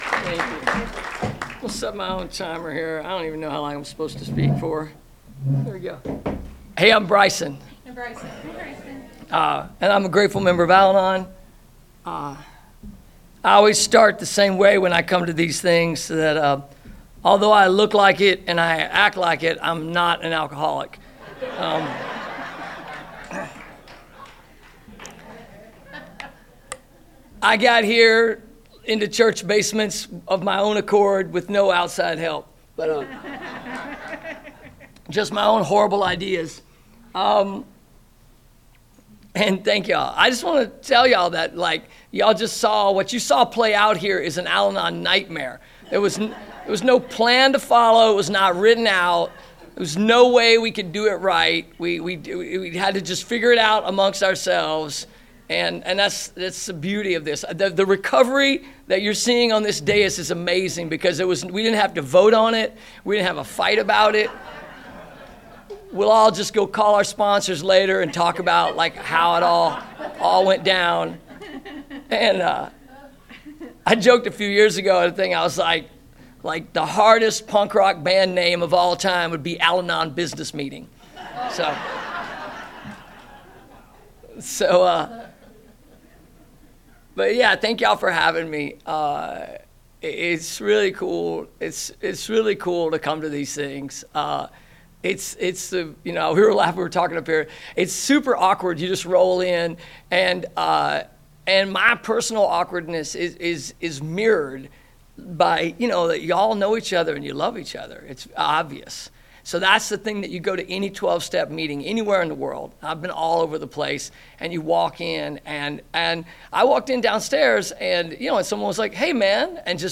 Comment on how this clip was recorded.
Encore Audio Archives - 12 Step Recovery 49th Annual Antelope Valley Roundup - Palmdale